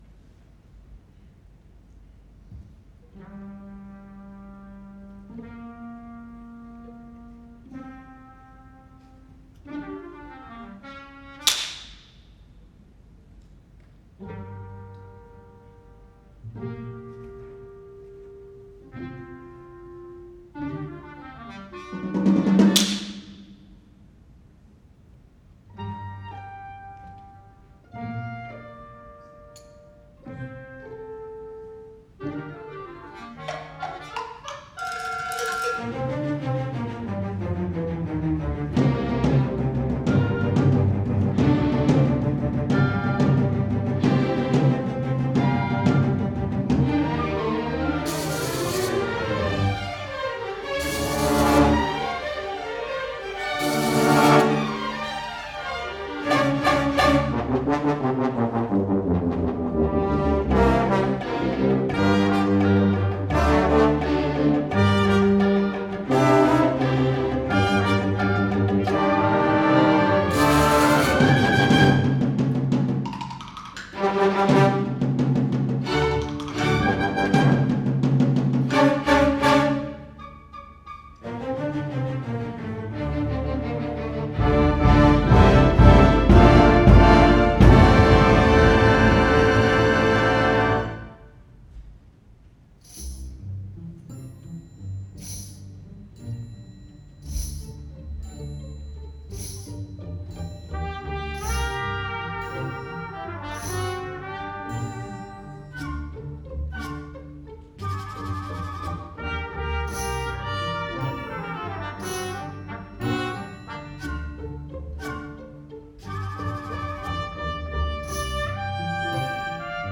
for Orchestra (1994)
And the percussion section is kept especially busy.